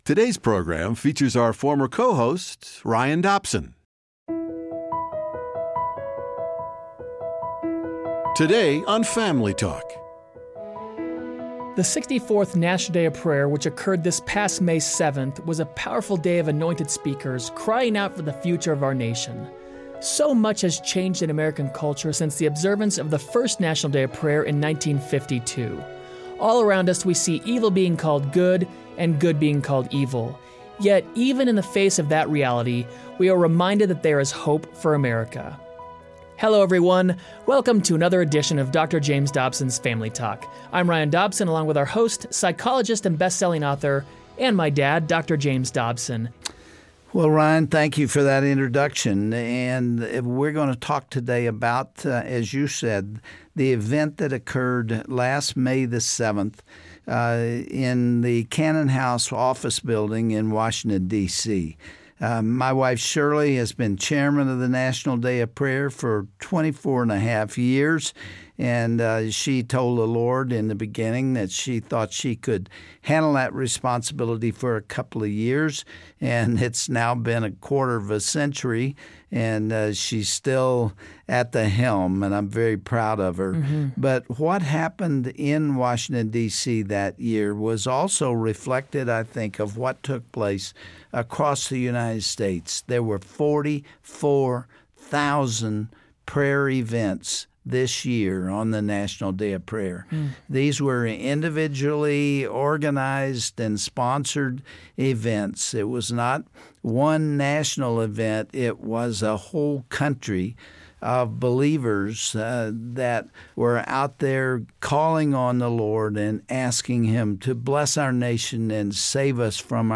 Host Dr. James Dobson
Guest(s):Chaplain Barry Black and Alex Kendrick